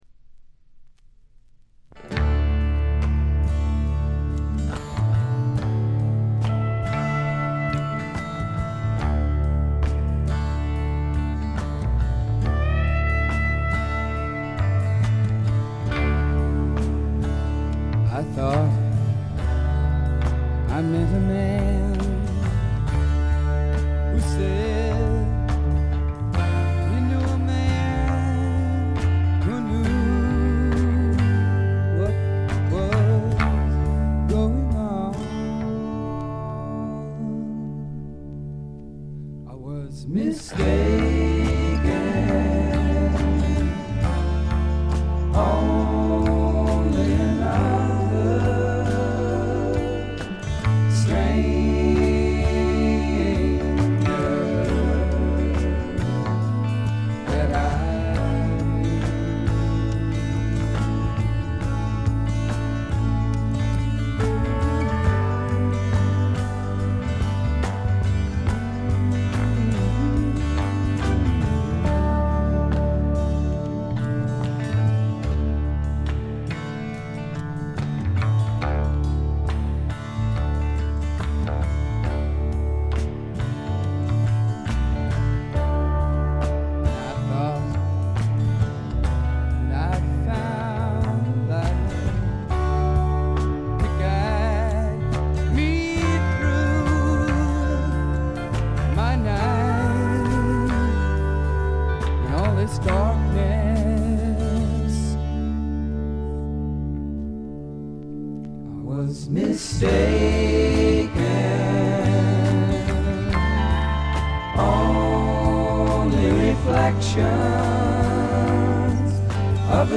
静音部で軽微なバックグラウンドノイズや軽微なチリプチ少々。
試聴曲は現品からの取り込み音源です。